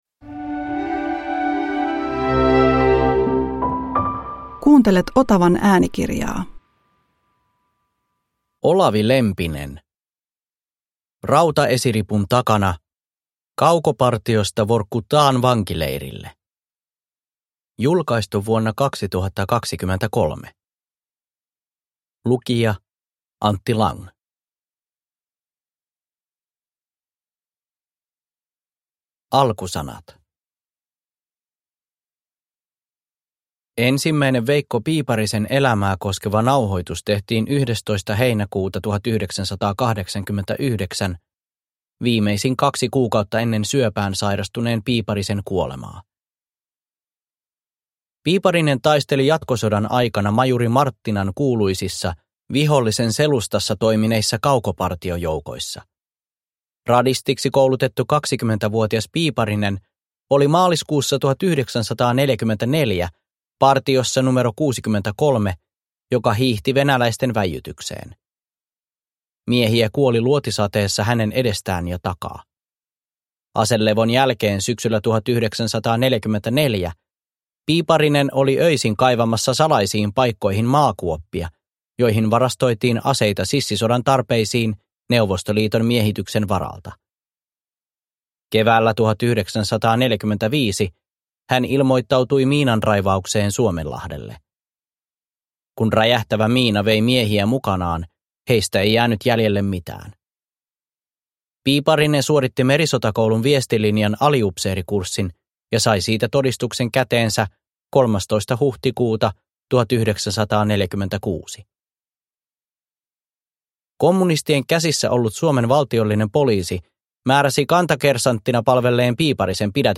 Rautaesiripun takana – Ljudbok – Laddas ner